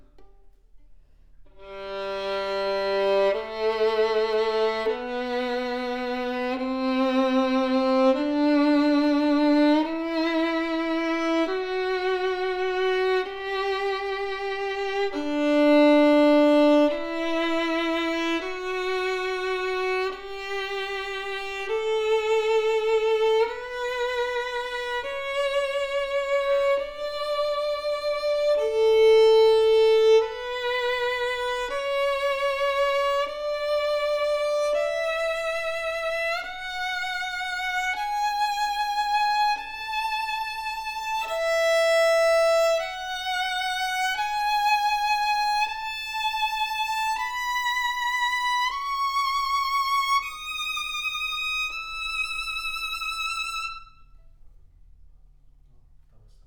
Audio file ( Scale ):
Deep, rounded, booming tone that sings with great depth. Rich ringing in mid range, strong projection that speaks with clear definition. Focused brilliant  E string with great sustain, sonorous and projective tone as the audio clip shows, big sounding violin that can easily fill the hall.